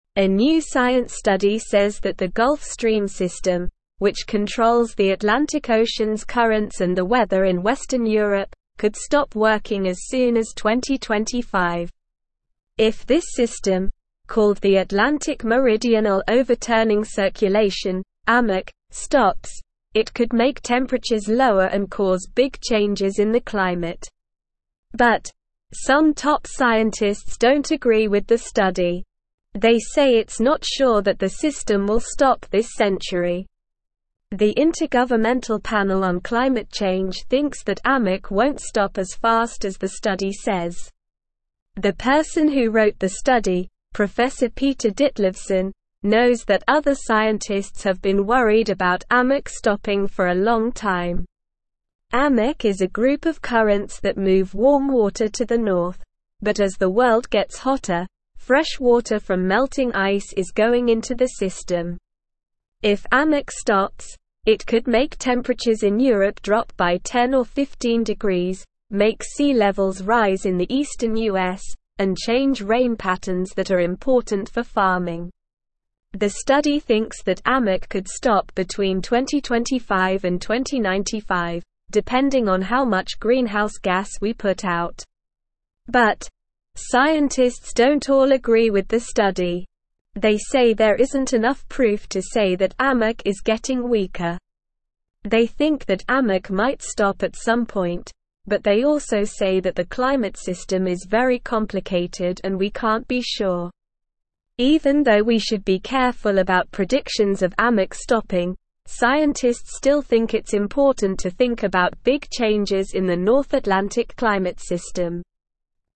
Slow
English-Newsroom-Lower-Intermediate-SLOW-Reading-Gulf-Stream-System-May-Stop-Changing-Climate.mp3